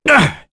Roi-Vox_Attack6.wav